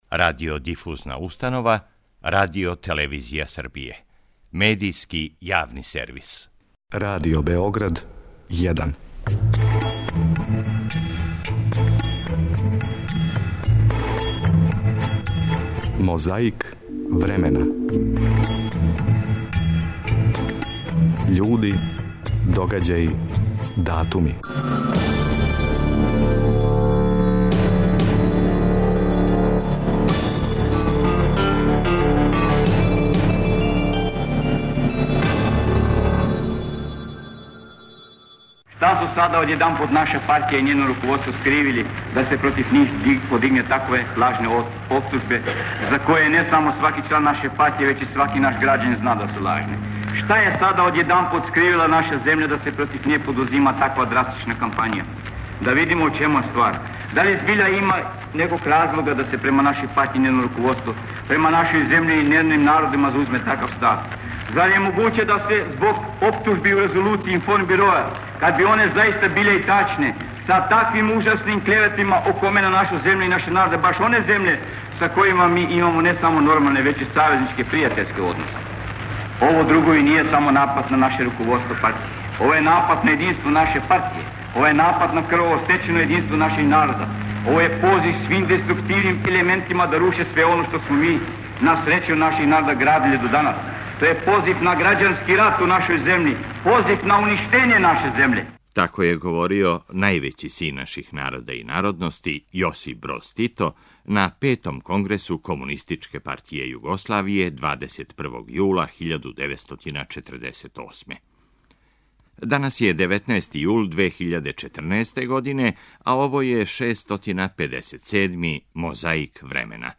Звучна коцкица са датумом 21. јул 1948. подсећа како је говорио највећи син наших народа и народности Јосип Броз Тито на Петом конгресу Комунистичке партије Југославије.
Микрофони су забележили заклетву, инаугурациони говор, химну...